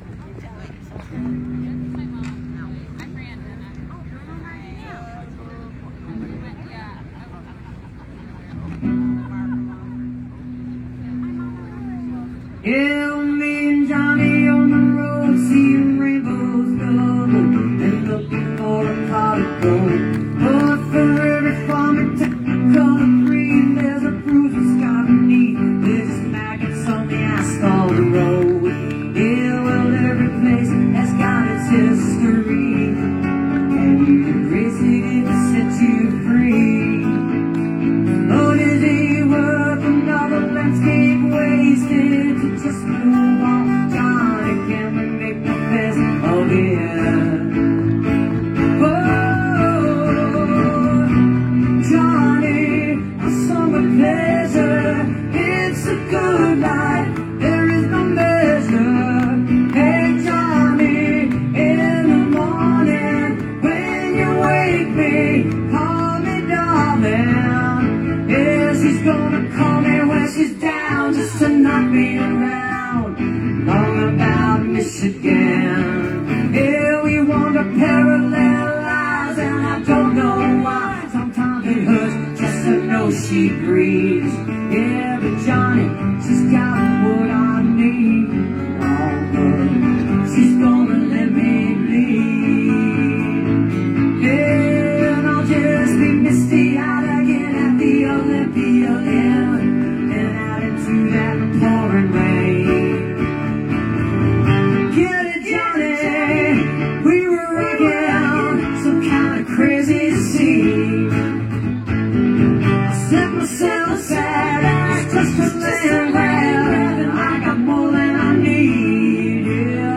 (captured from the facebook livestream of part of the show)